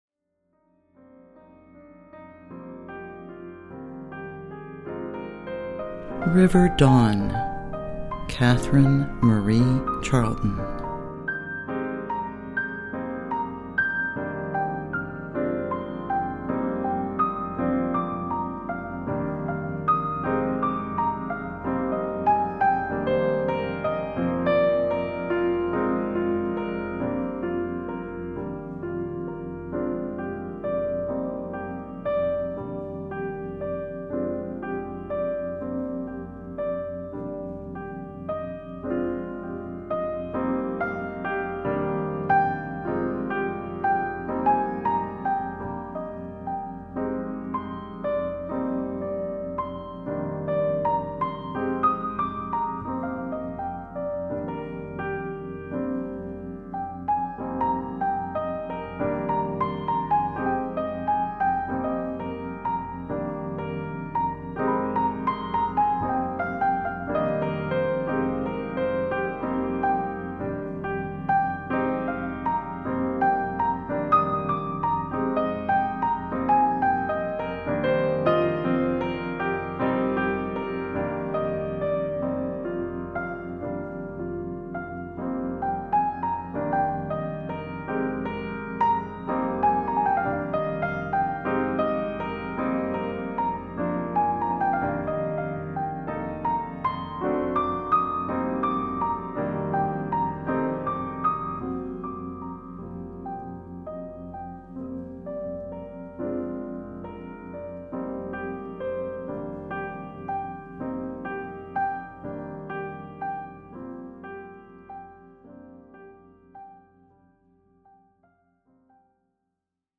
Intimate piano meditations to soothe and nurture the soul.
Instruments featured: solo piano.